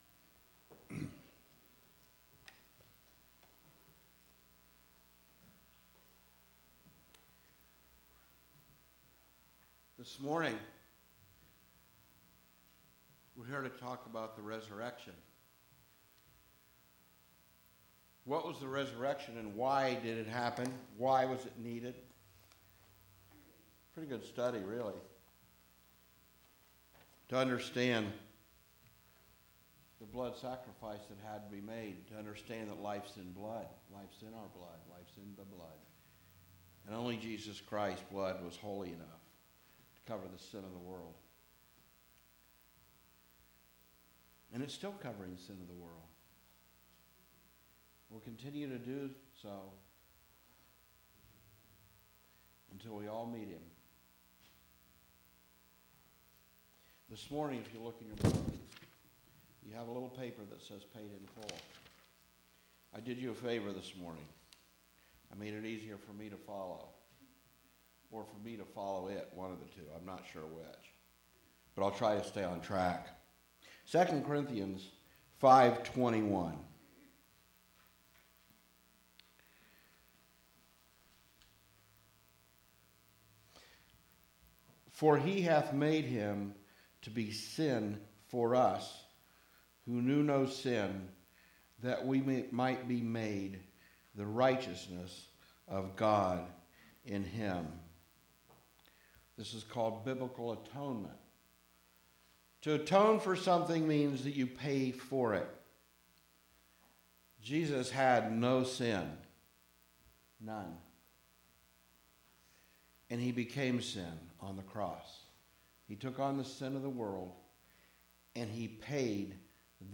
Sermons | Community Christian Fellowship